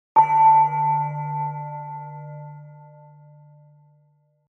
Chime-sound1.mp3